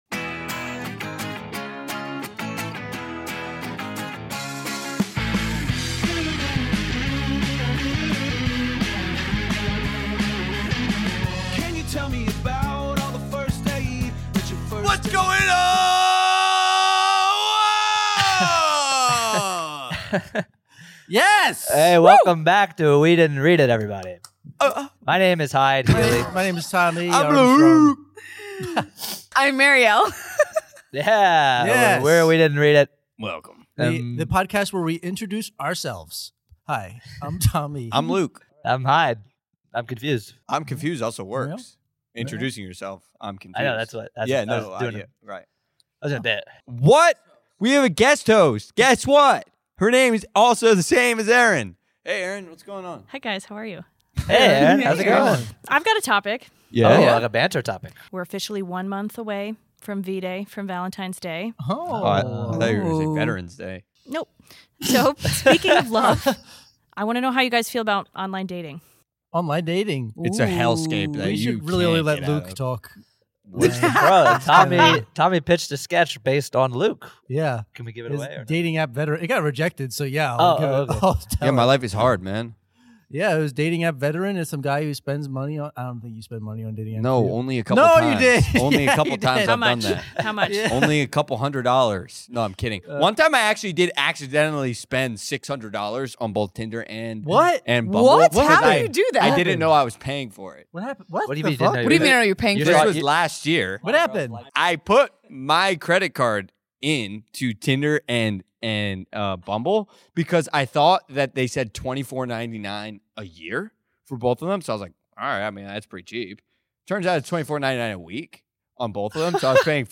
Take a trip into the OASIS with the We Didn't Read It crew as we improvise Ernest Cline's Ready Player One.